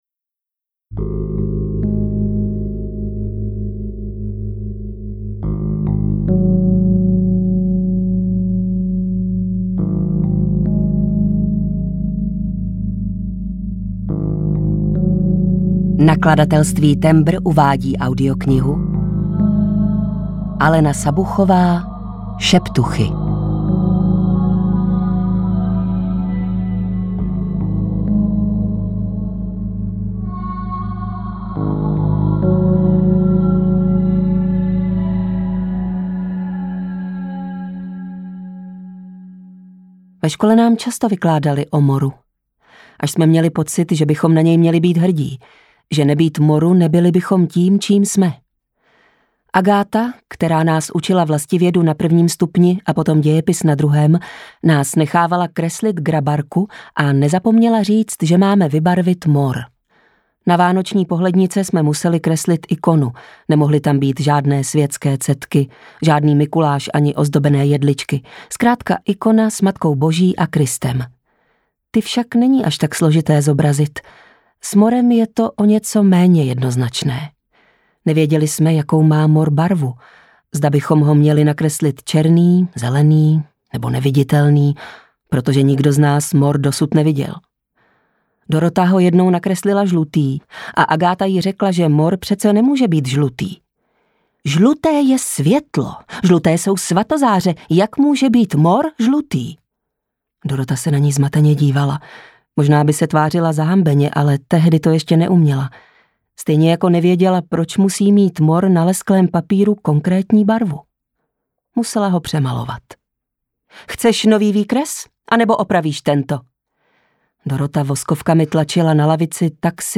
Audiokniha
Čte: Dana Černá